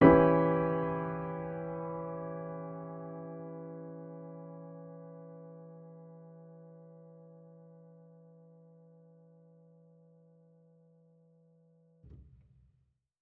Index of /musicradar/jazz-keys-samples/Chord Hits/Acoustic Piano 2
JK_AcPiano2_Chord-Em6.wav